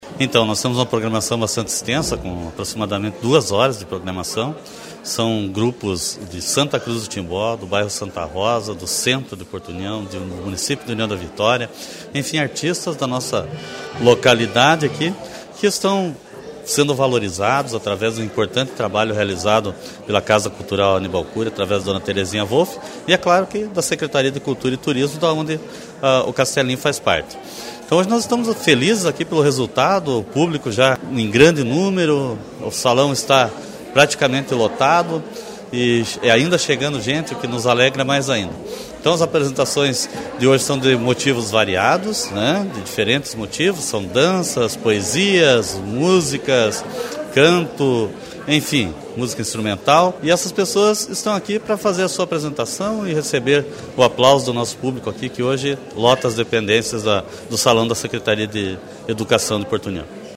O secretario de Cultura e Turismo de Porto União, José Carlos Gonçalves, esteve prestigiando a Tertúlia
CULTURA-JOSÉ-CARLOS-01-TERTÚLIA-NA-SEC.-EDUCAÇÃO.mp3.mp3